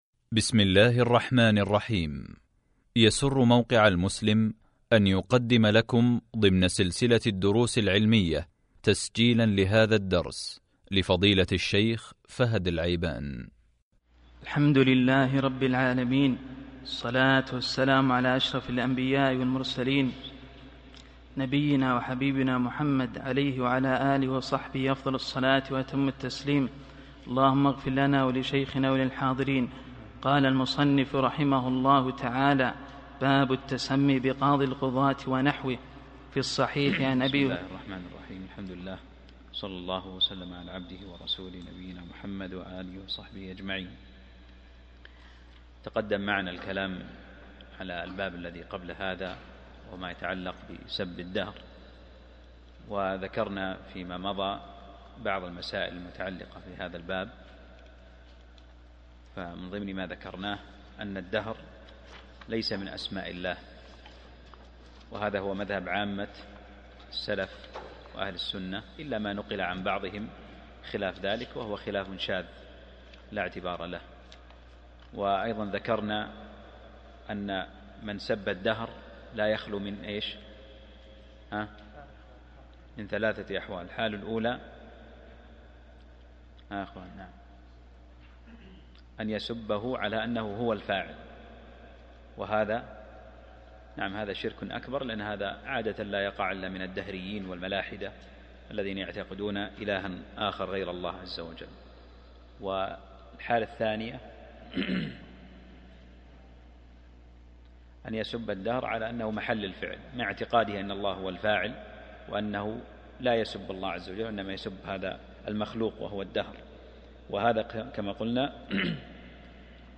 الدرس 43 من شرح كتاب التوحيد | موقع المسلم